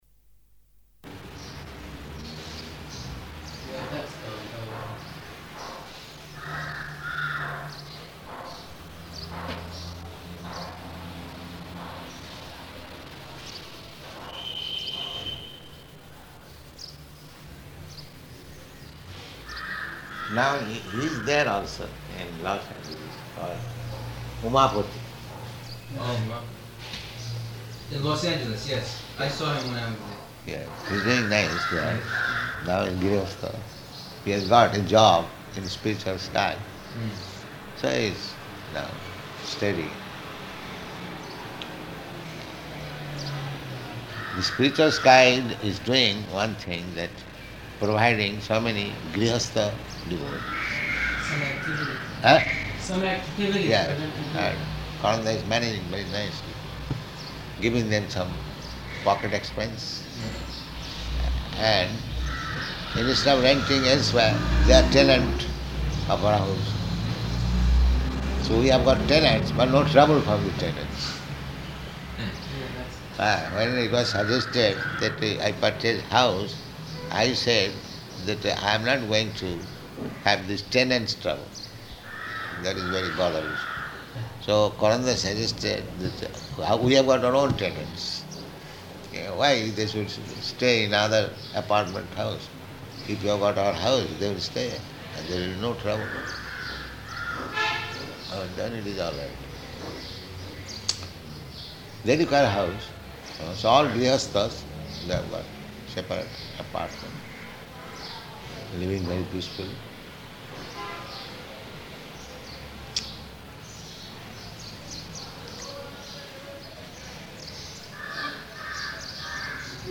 Room Conversation
Location: Bombay